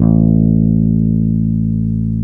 A SUS.wav